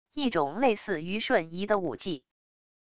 一种类似于瞬移的武技wav音频